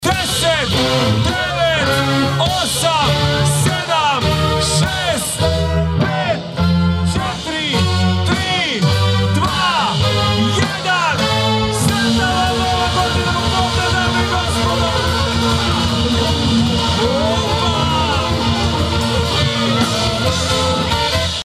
U Labinu je ispred Alter Ega, uz glazbu Night Expressa i humanitarnu notu, točno u podne dočekana Nova 2026. godina.
Vesela atmosfera i bogata ponuda obilježili su još jedan tradicionalni podnevni doček.